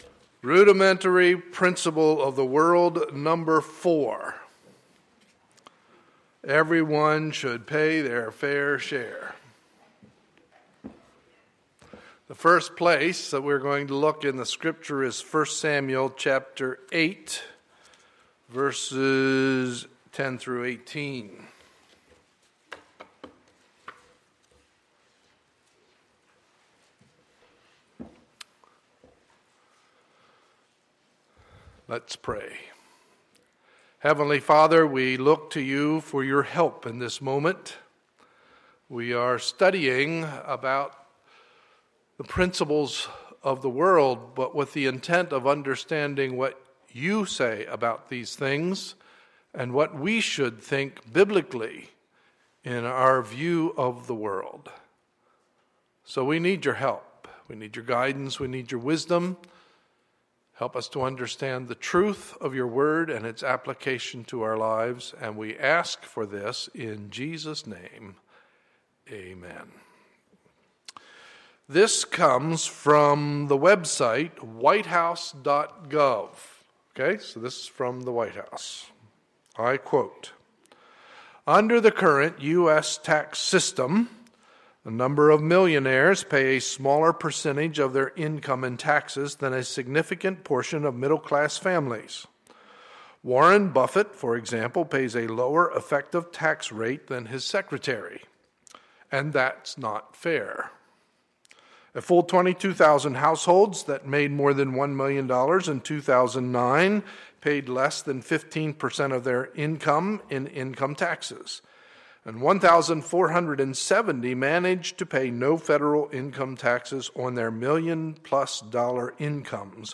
Sunday, March 30, 2014 – Morning Service
Sermons